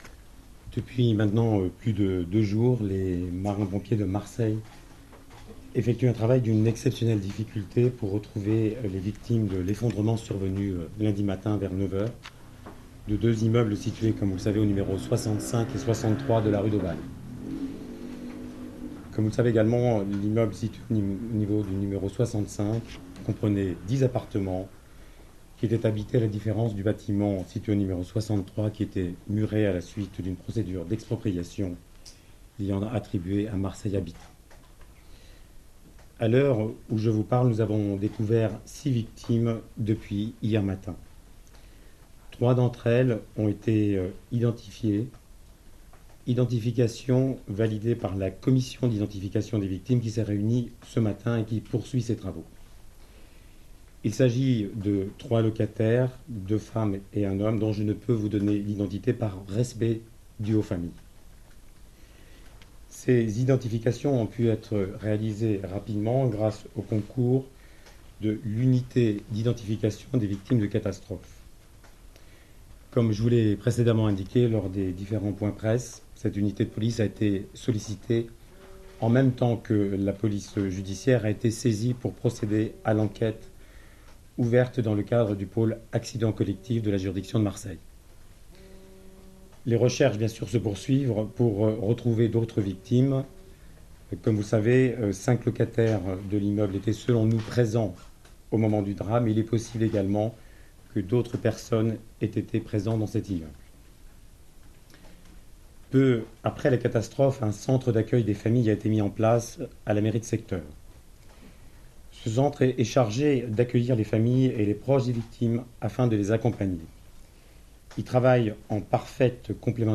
A la suite de l’effondrement de trois immeubles à Marseille, le procureur de la République s’est exprimé sur l’enquête. «En l’état il est prématuré de pouvoir établir et d’imputer des responsabilités pénales. A ce stade, les causes de l’effondrement ne sont pas établies et on ignore d’ailleurs lequel des deux immeubles l’a provoqué», a déclaré Xavier Tarabeux, lors d’une conférence de presse donnée, ce mercredi, au côté d’Eric Arella, directeur interrégional de la police judiciaire.